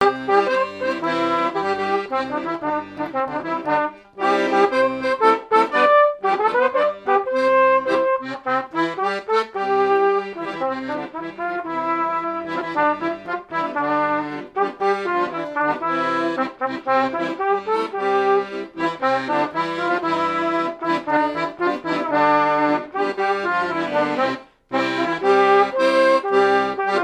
Quadrille - Pastourelle
danse : quadrille : pastourelle
airs de danses issus de groupes folkloriques locaux
Pièce musicale inédite